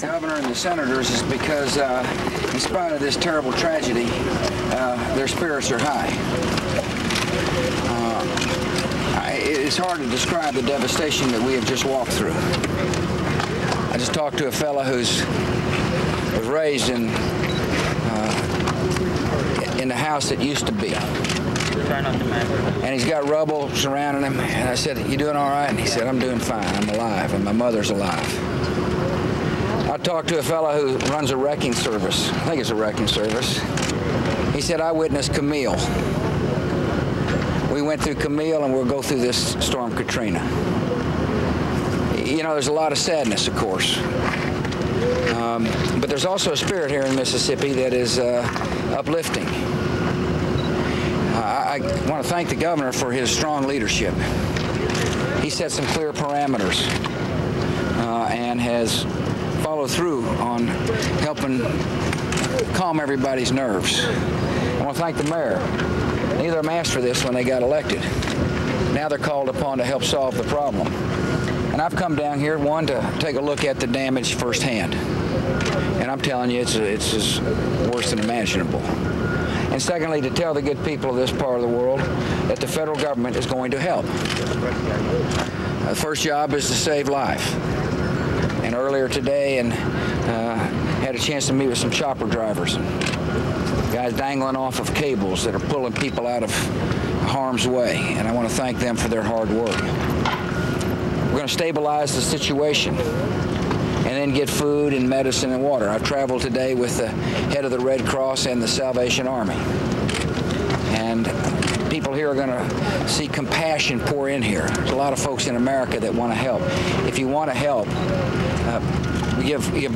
U.S. President George W. Bush speaks in Mississippi on the devastation of Hurricane Katrina